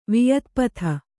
♪ viyatpatha